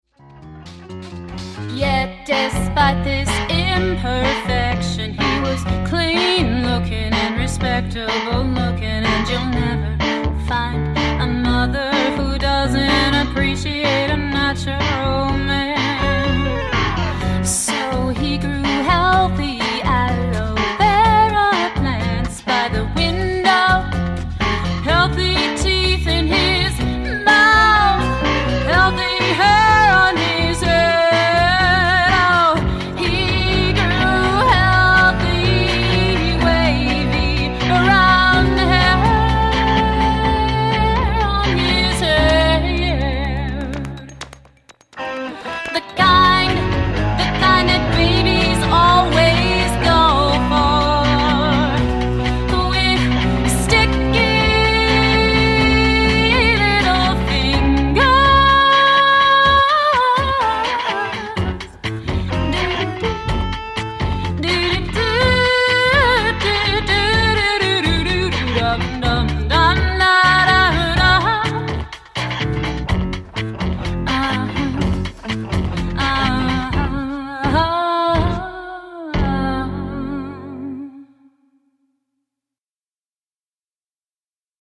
keys/vocals
guitar
bass
West Hartford, CT